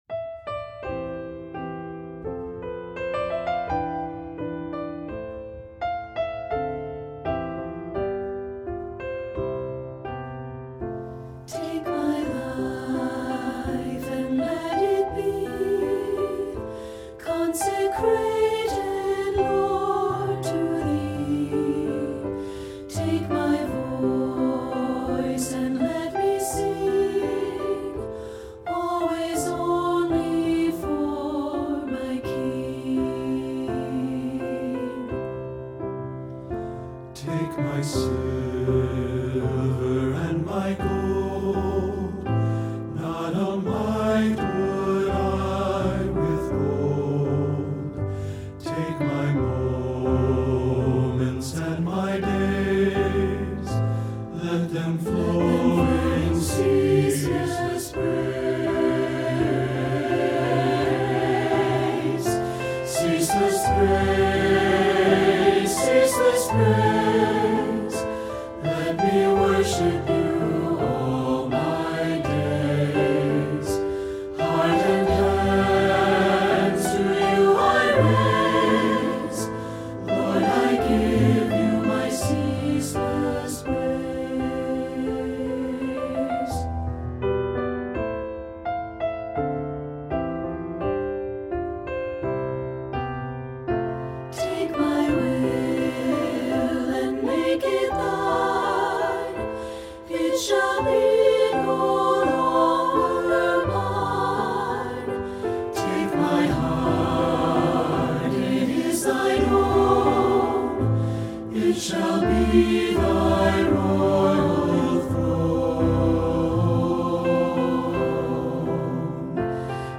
Voicing: SAB a cappella